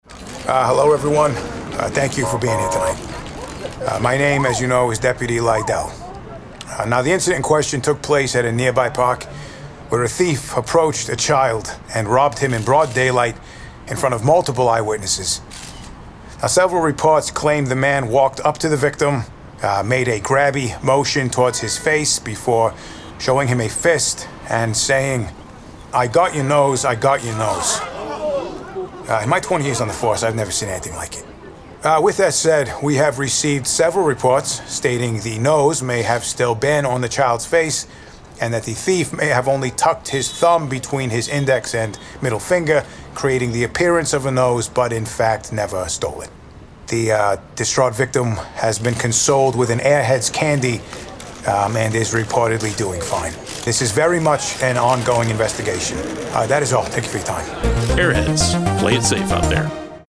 Idea 2. Airheads Candy Radio Spot